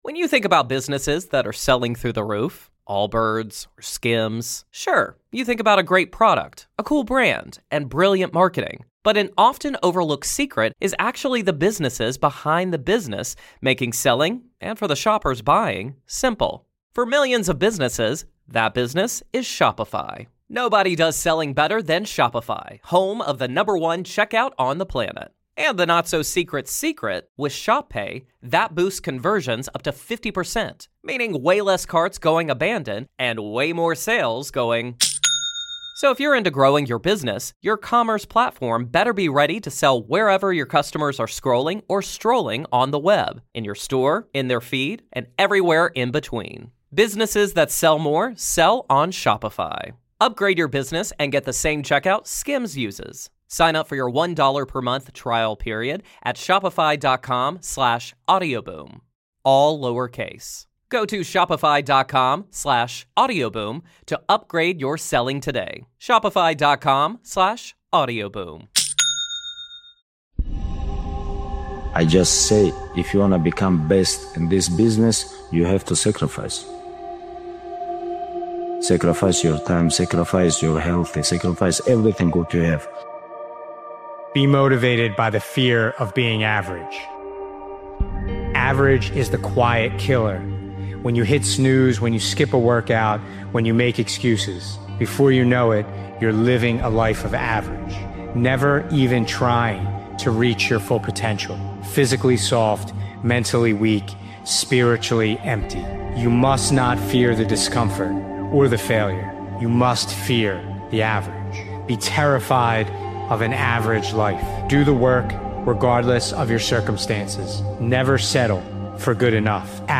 Season 23, Episode 40, Sep 27, 06:12 PM Headliner Embed Embed code See more options Share Facebook X Subscribe This powerful motivational speeches compilation is a reminder that success doesn’t always happen overnight—but with discipline, consistency, and the right mindset, it will happen. Every setback is part of the process; every small step builds momentum. Stay patient, stay relentless, and know that victory is only a matter of time.